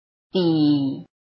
臺灣客語拼音學習網-客語聽讀拼-詔安腔-單韻母
拼音查詢：【詔安腔】bi ~請點選不同聲調拼音聽聽看!(例字漢字部分屬參考性質)